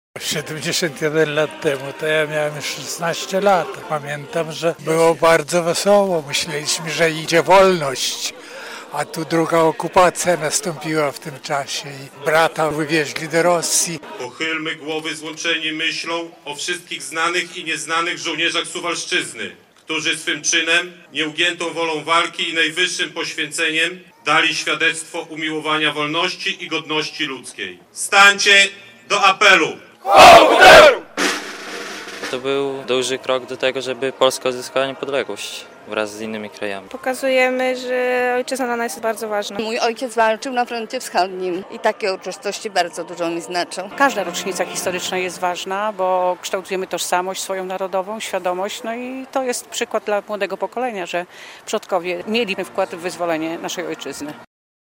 Była salwa honorowa, apel poległych i złożenie kwiatów – tak 71. rocznicę zakończenia II wojny światowej obchodzili mieszkańcy Suwałk.
Uroczystości odbyły się przed Pomnikiem Bohaterów Września 1939 roku przy ul. Wojska Polskiego.